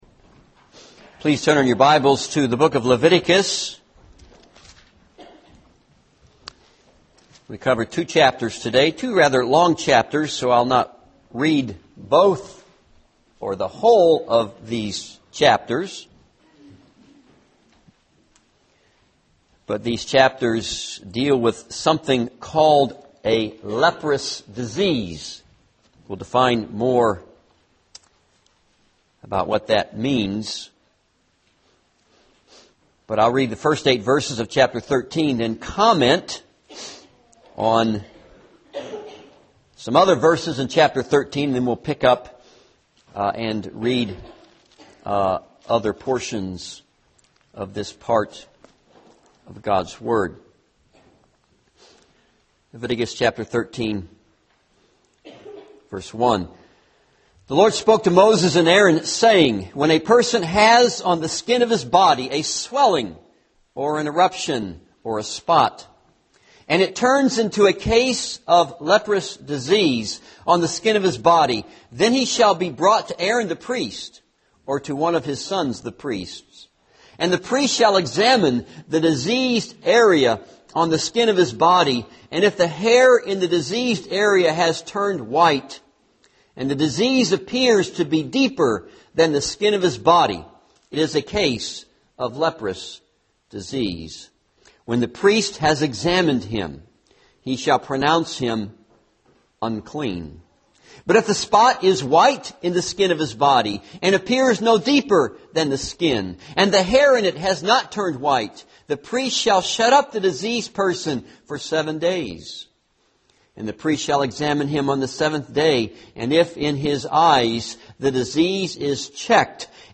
This is a sermon on Leviticus 13-14.